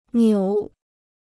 扭 (niǔ): to turn; to twist.